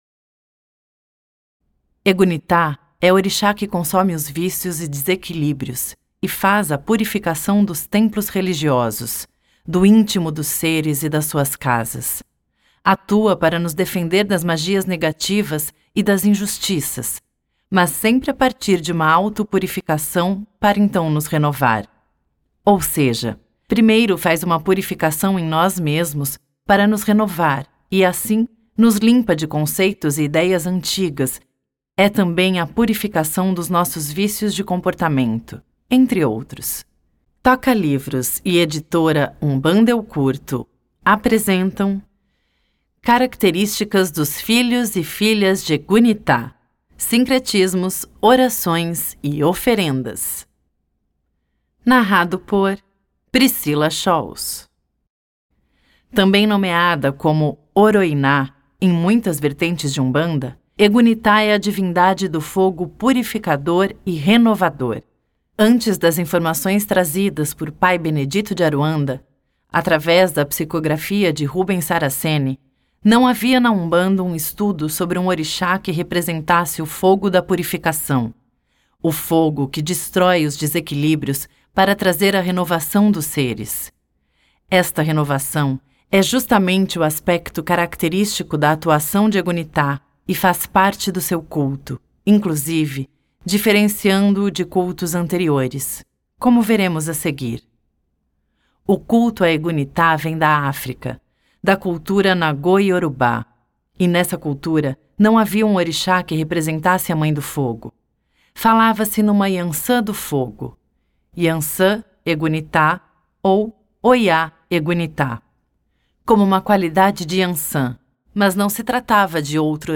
Atualização: 4 de outubro de 2021 Facebook Twitter Pinterest LinkedIn Categoria: Audiobook Tags: Egunitá , Orixá de Cabeça , Orixás , Oroiná , Umbanda